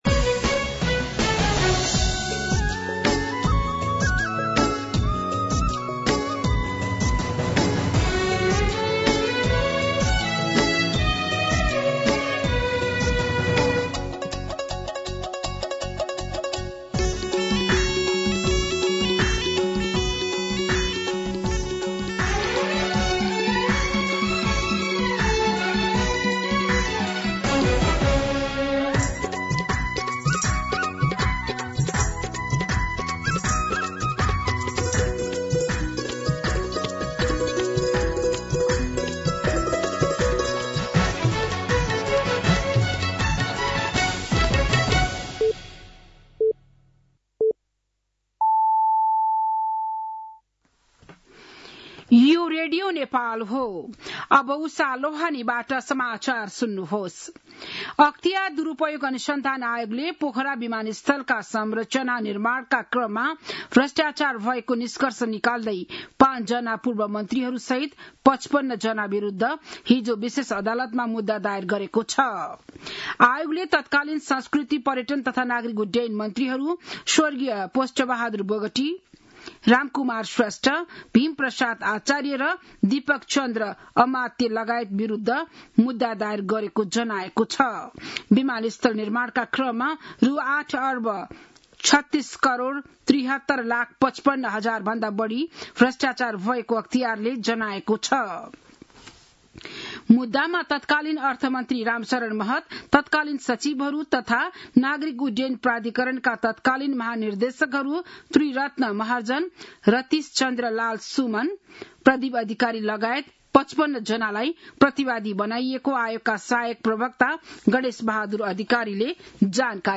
बिहान ११ बजेको नेपाली समाचार : २२ मंसिर , २०८२